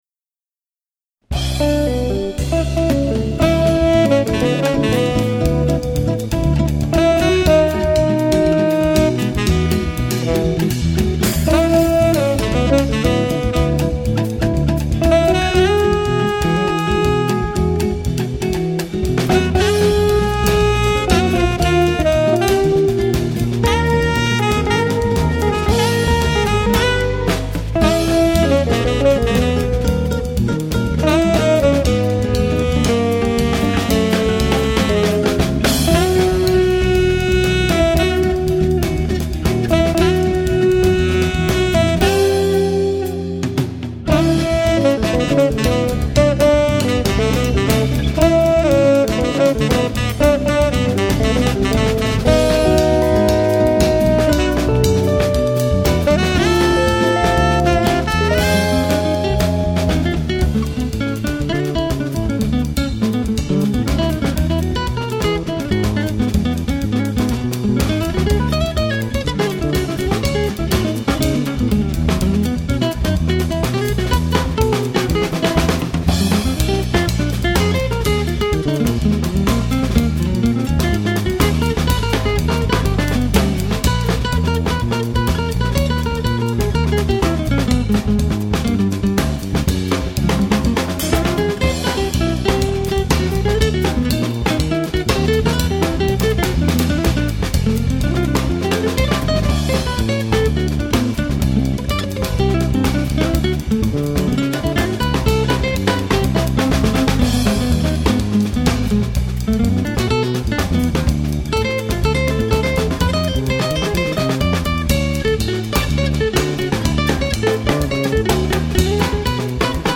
chitarra
batteria
basso
sax